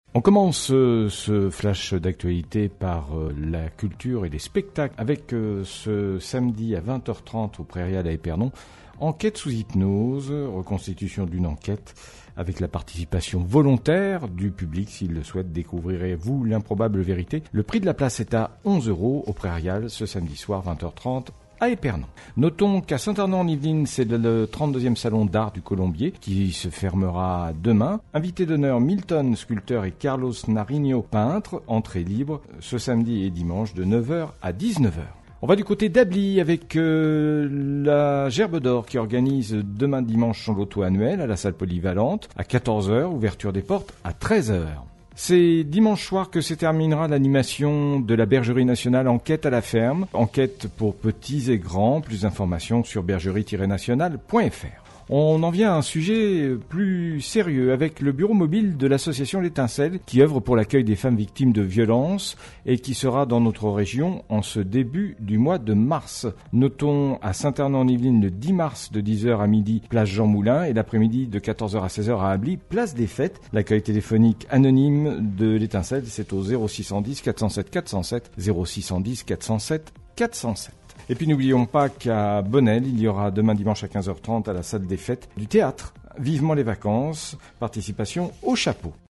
07.03-FLASH-LOCAL-MATIN.mp3